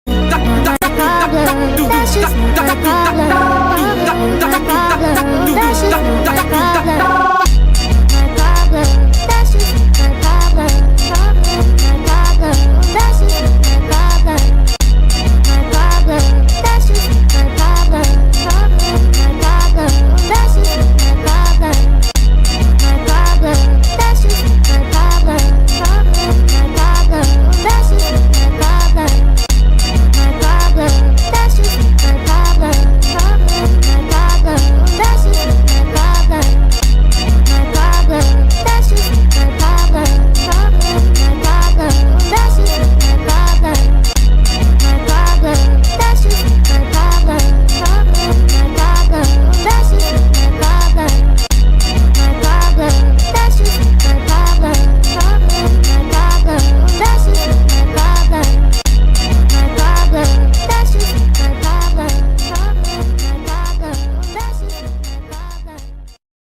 Brazilian Funk Remix